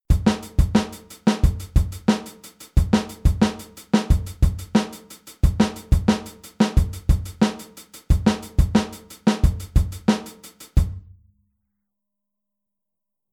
Aufteilung linke und rechte Hand auf HiHat und Snare
Bei diesem Groove haben wir in der Snare eine Dreigruppenaufteilung (ähnlich wie beim Bossa Nova) die du auf jeden Fall auch separat ohne den rechten Fuß üben solltest.
Einmal erst die Kick und dann die Snare und beim zweiten Mal genau umgekehrt.